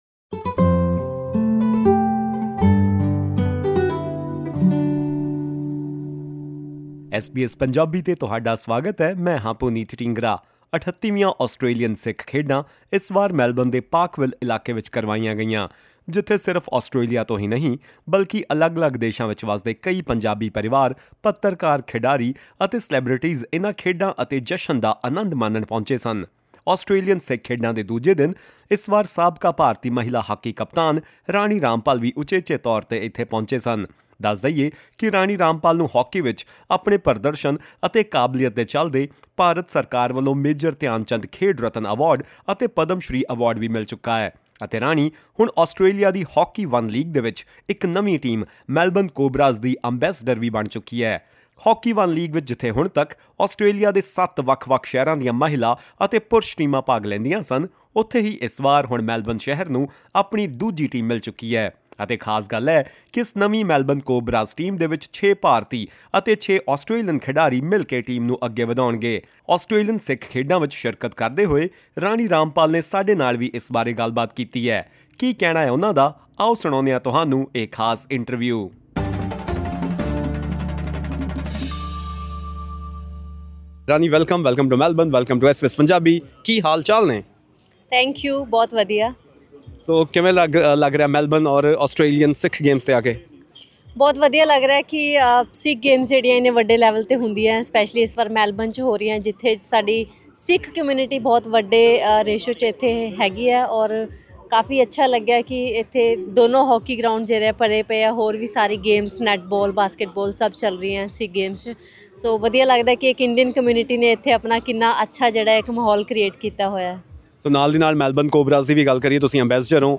Speaking to SBS Punjabi at the 38th Australian Sikh Games, former Indian women’s hockey captain Rani Rampal said she is proud to be an ambassador for the Hockey One League team Melbourne Cobras and urged migrant girls to believe in their dreams.